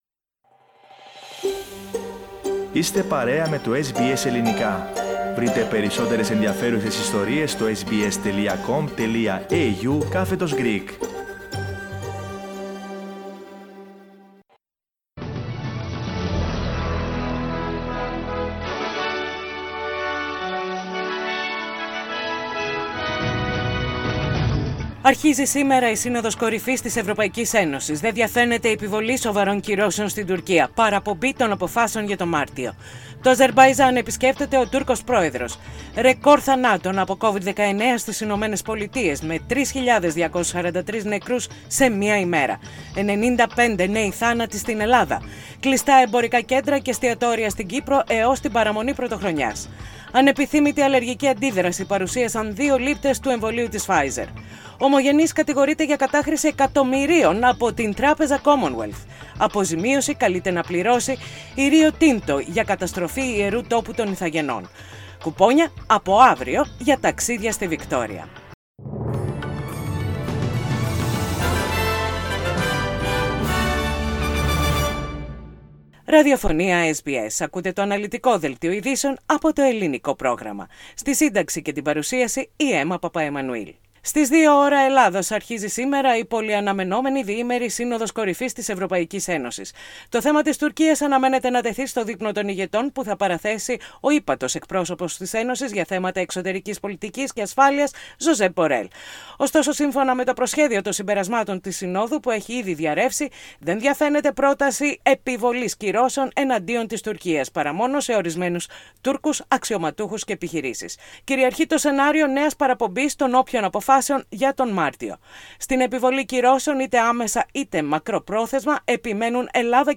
Δελτίο ειδήσεων - Πέμπτη 10.12.20